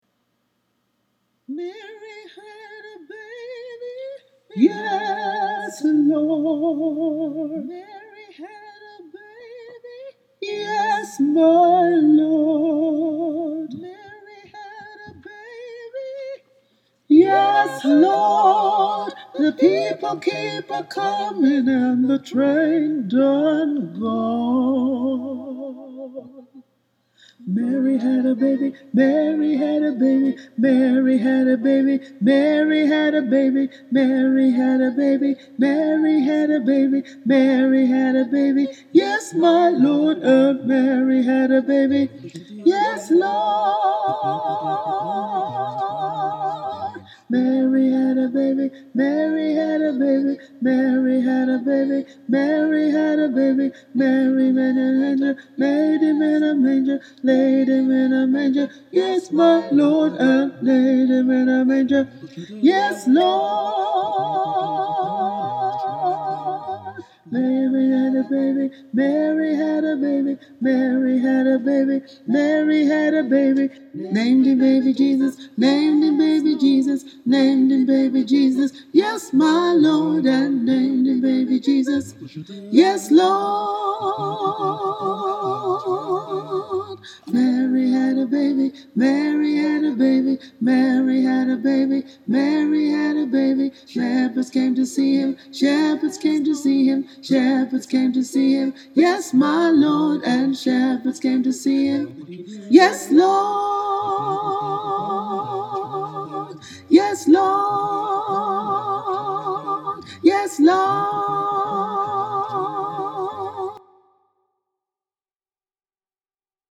mary-had-a-baby-tenor.mp3